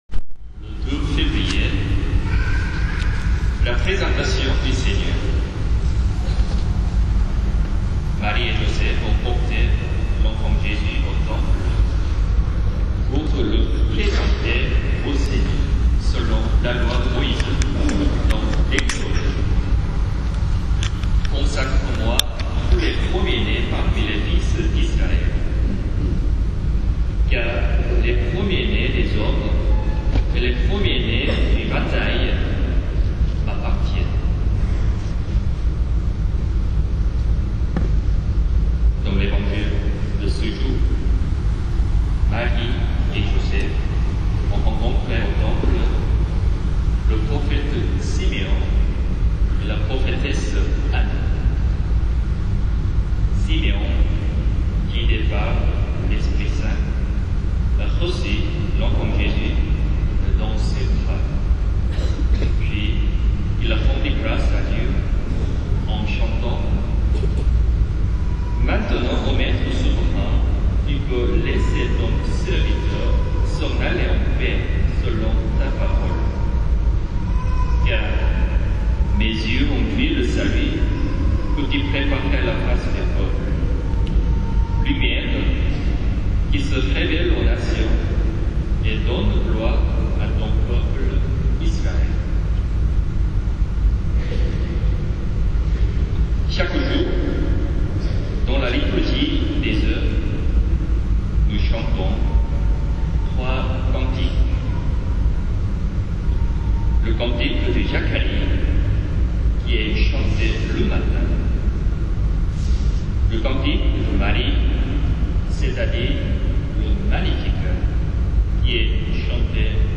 homélies